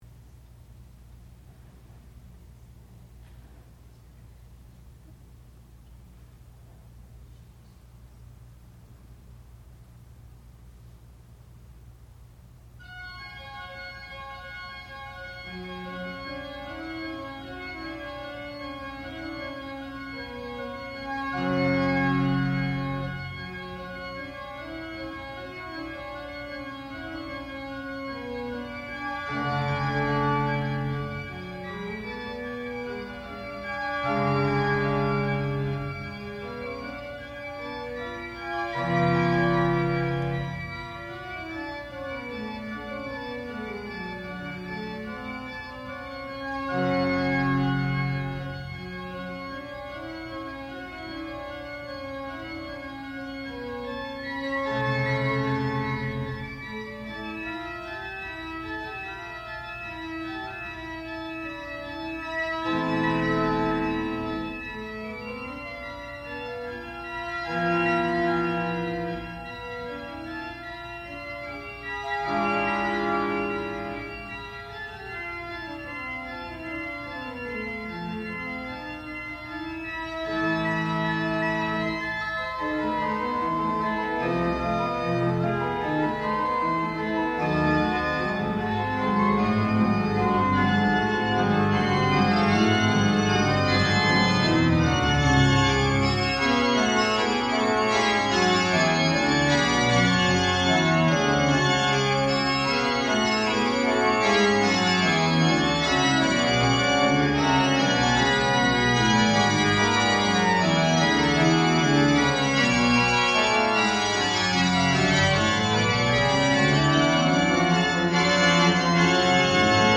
sound recording-musical
classical music
Graduate Recital
organ